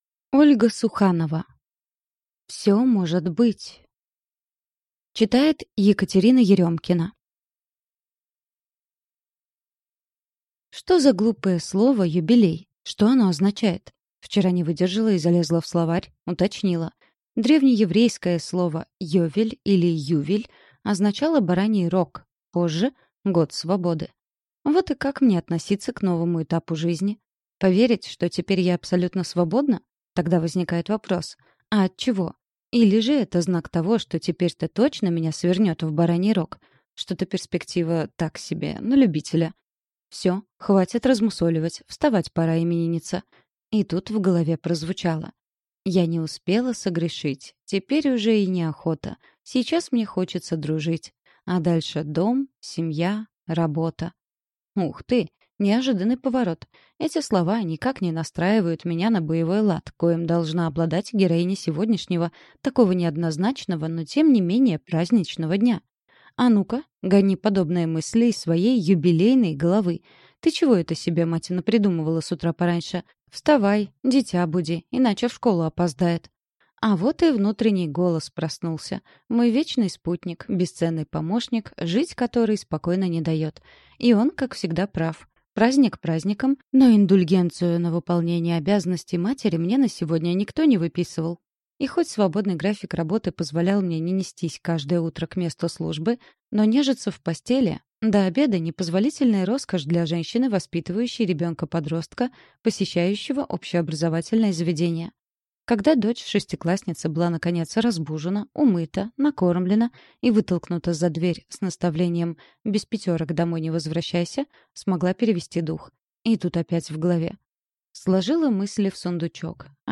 Aудиокнига Всё может быть…